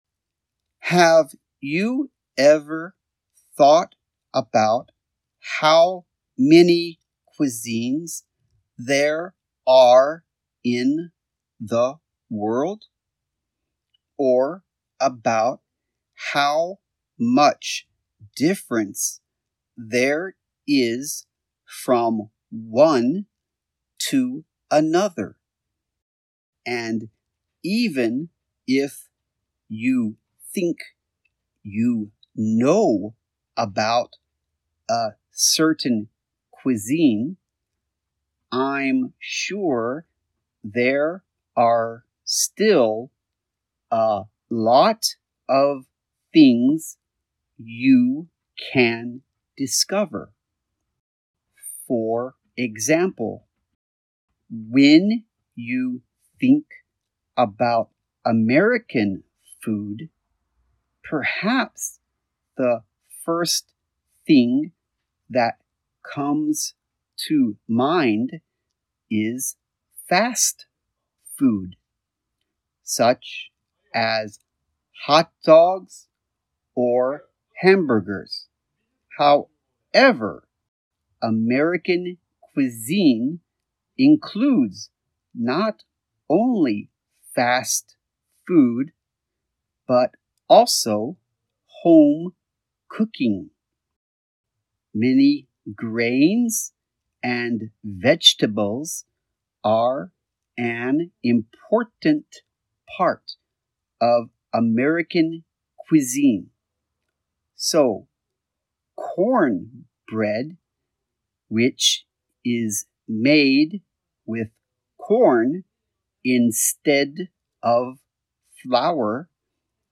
※ 課題文を録音した音声を、こちらよりダウンロードできます。
Advanced(速度：ゆっくり)
Speech-Contest_2024_Advanced_slow.mp3